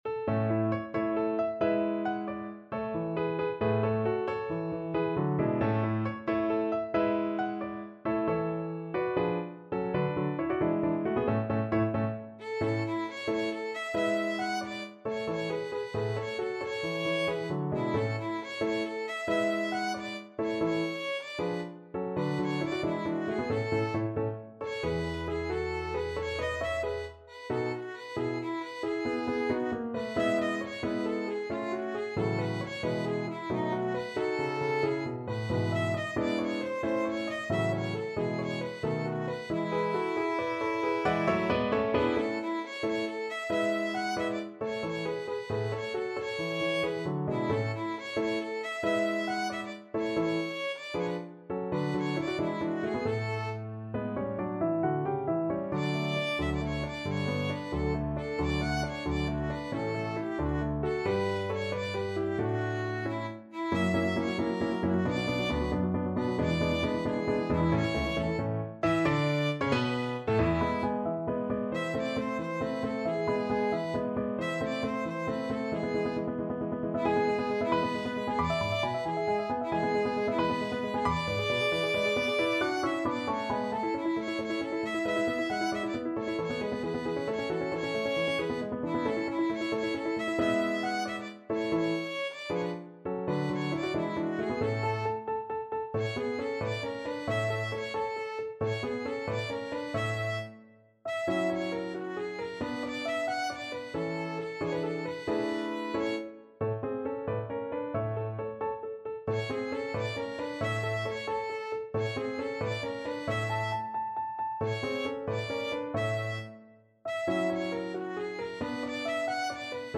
Violin
A major (Sounding Pitch) (View more A major Music for Violin )
. = 90 Allegretto vivace
Classical (View more Classical Violin Music)